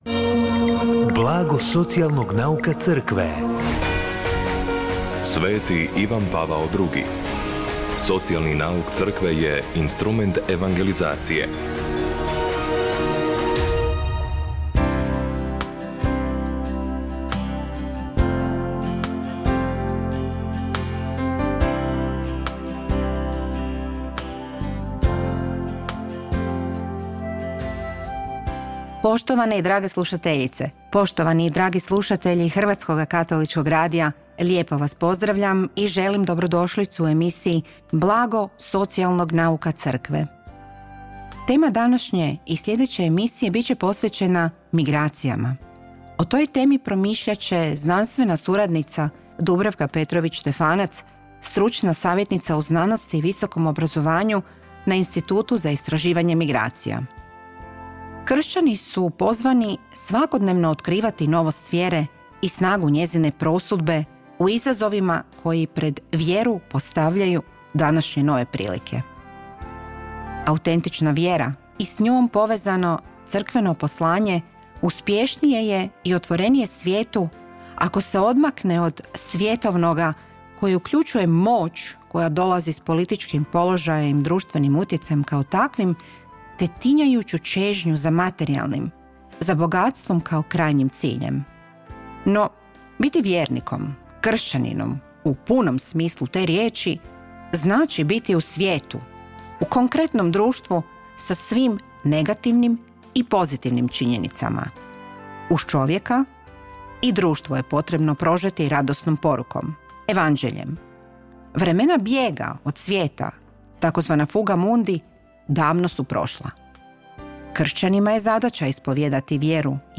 Emisiju na valovima HKR-a “Blago socijalnog nauka Crkve” subotom u 16:30 emitiramo u suradnji s Centrom za promicanje socijalnog nauka Crkve Hrvatske biskupske konferencije.